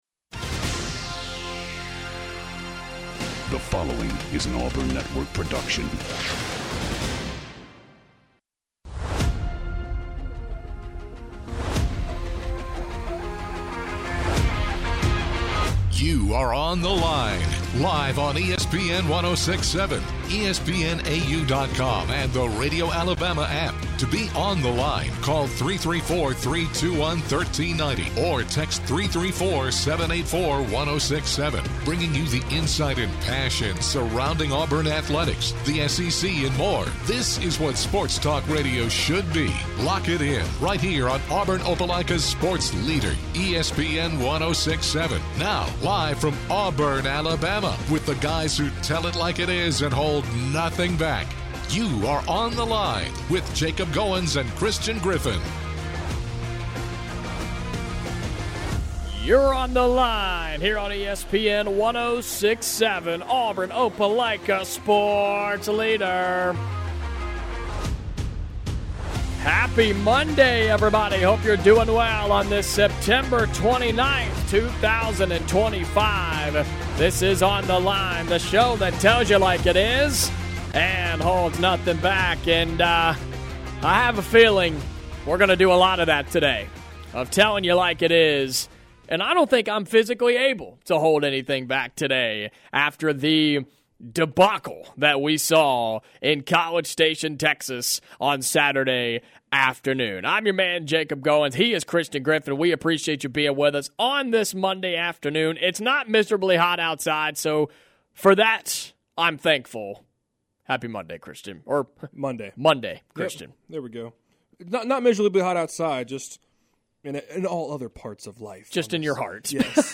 the guys take numerous phone calls and read texts from angry fans who are starting to lose faith in Auburn and Hugh Freeze. Can Auburn Football turn it around, or is Auburn barrelling down the path of firing another coach?